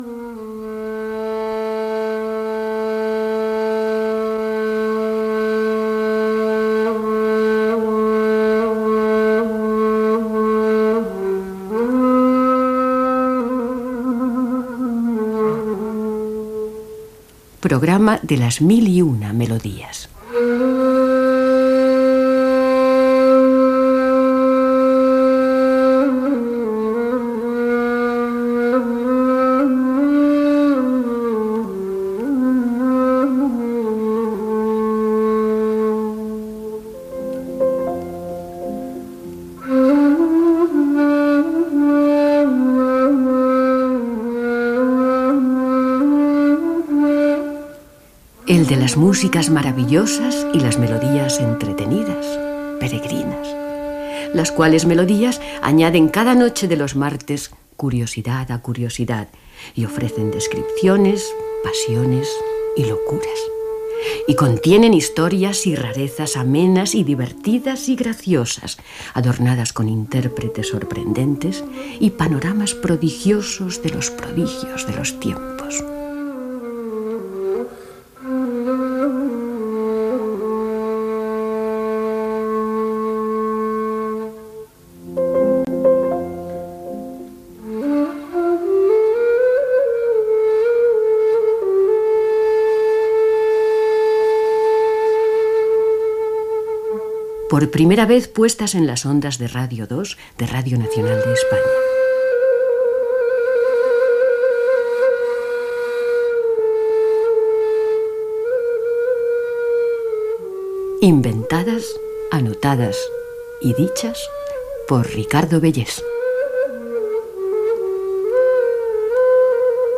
Careta del programa, narració "Ring, ring, Silvestre" Gènere radiofònic Musical